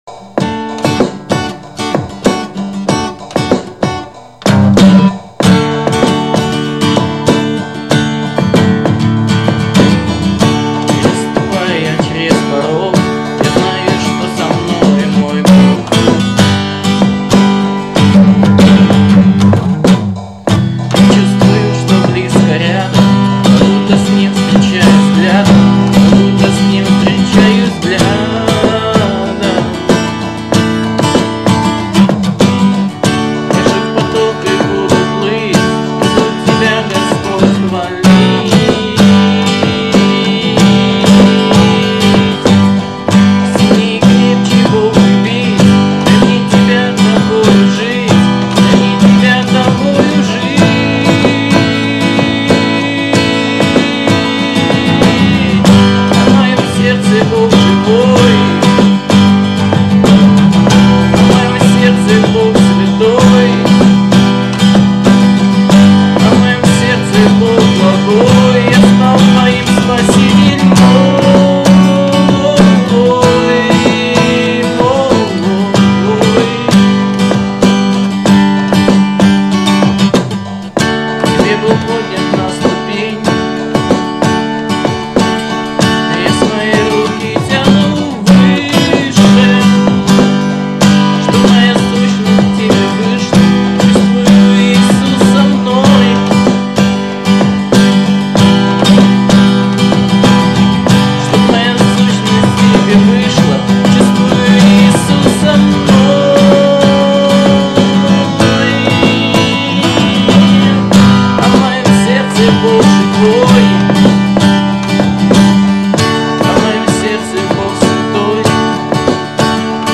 песня
84 просмотра 110 прослушиваний 1 скачиваний BPM: 96